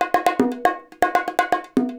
120 BONGOS3.wav